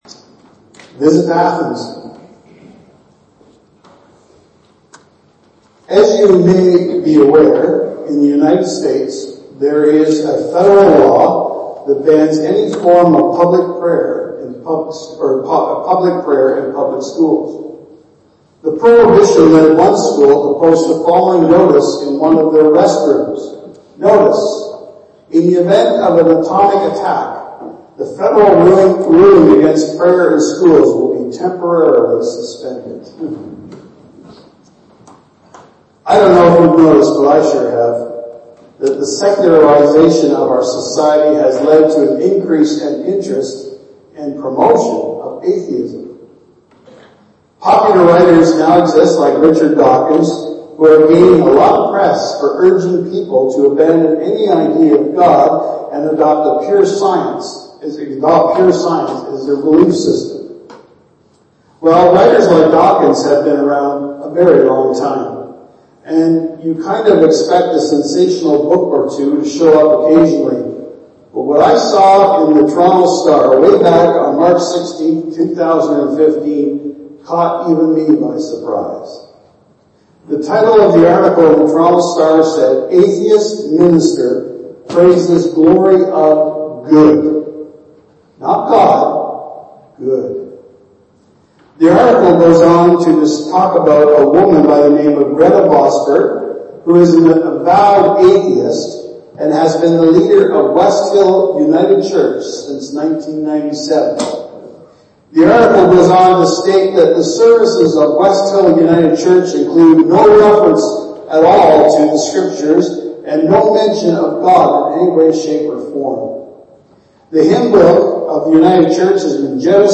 Archived Sermons